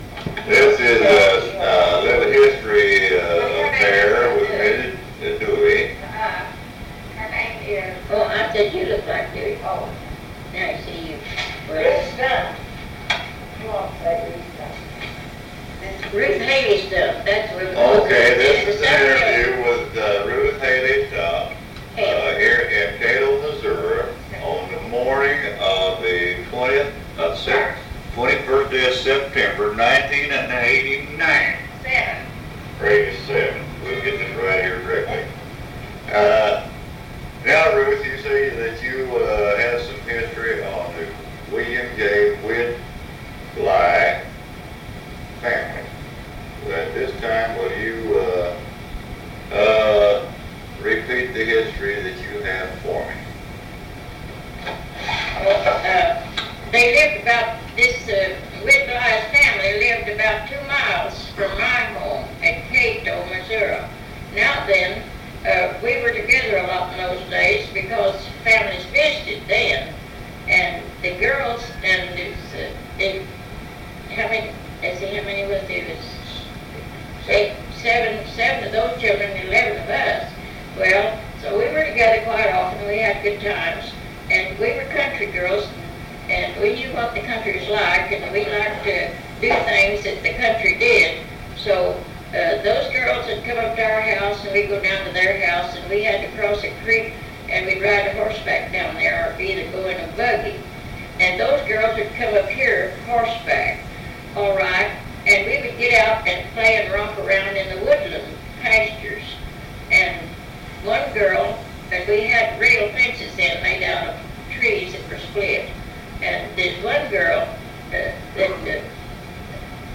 Oral History Archive | Family Histories